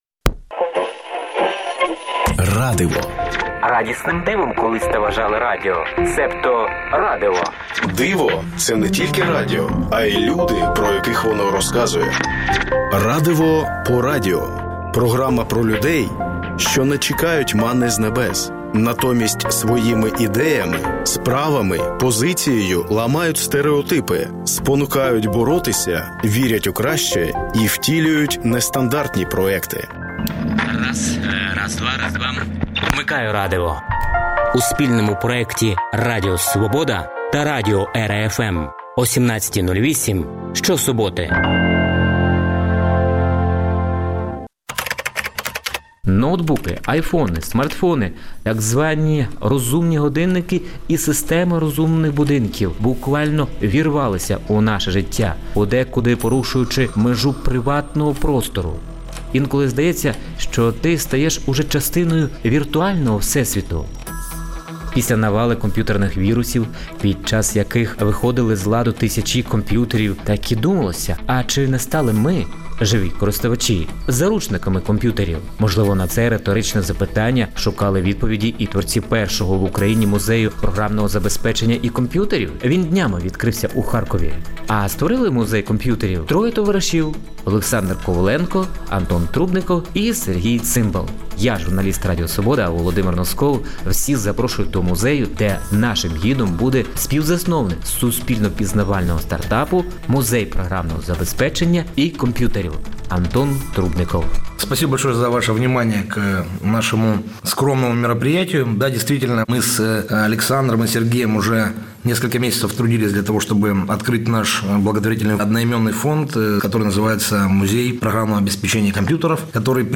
«Радиво» по радіо – це спільний проект Радіо Свобода та радіо «ЕРА-Fm» що присвячується людям, які не чекають манни з небес, натомість своїми ідеями, справами, позицією ламають стериотипи, спонукають боротися, вірять у краще і втілюють нестандартні проекти. Вмикайте «Радиво» що-суботи О 17.08. В передачі звучатимуть інтерв’ю портретні та радіо замальовки про волонтерів, військових, вчителів, медиків, громадських активістів, переселенців, людей з особливими потребами тощо.